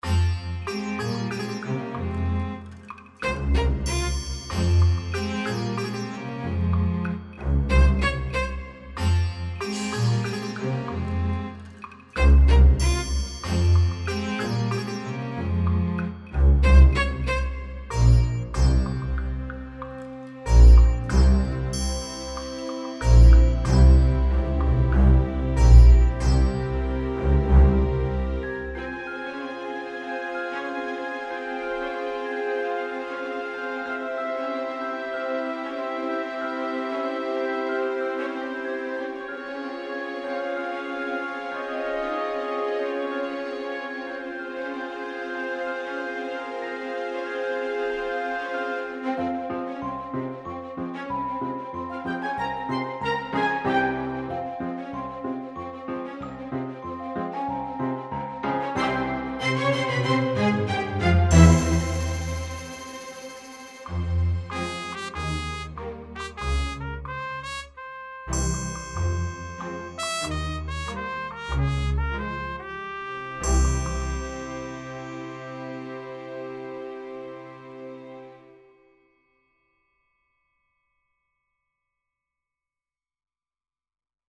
Largo [0-10] - - - -